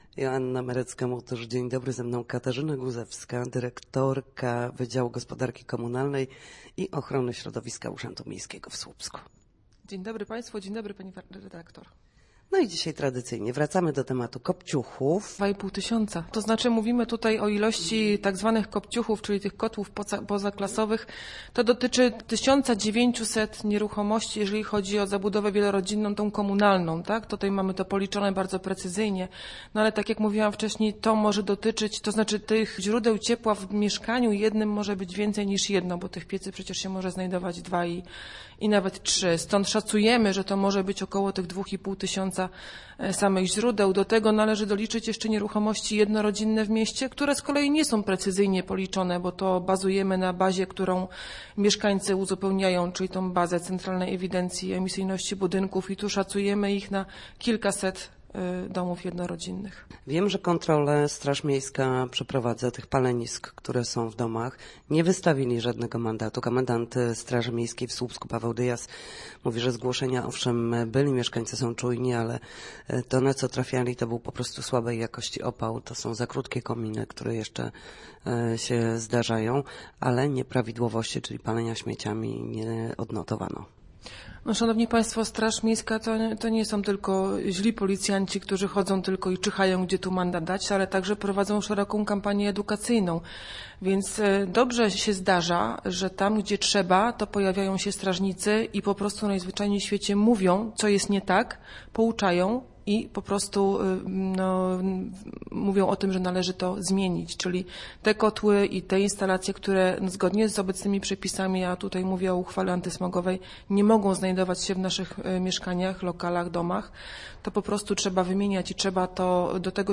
Na naszej antenie mówiła o zbliżającej się akcji Choinka za odpady oraz o wzroście dopłat do wymiany kopciuchów w Słupsku.